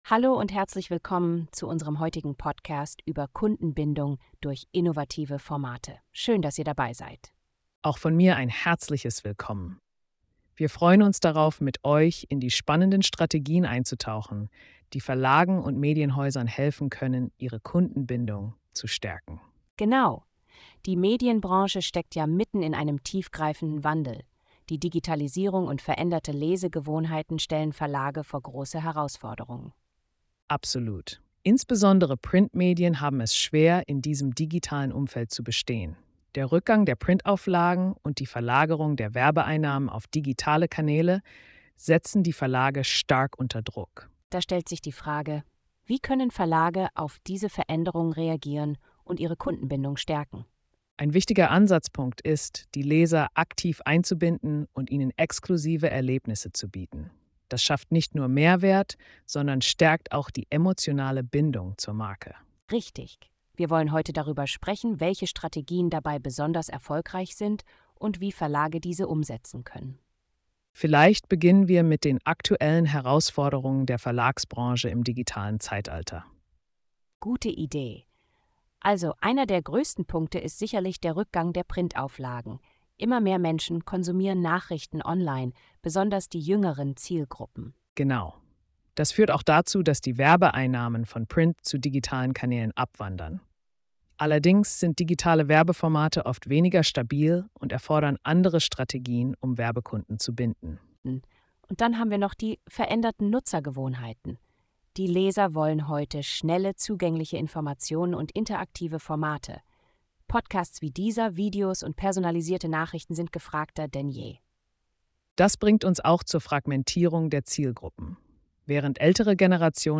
Unsere KI-Hosts diskutieren, wie sich Verlage im digitalen Wandel neu erfinden können.